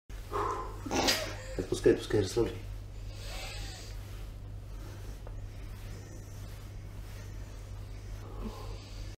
Chiropractor gives the LOUDEST back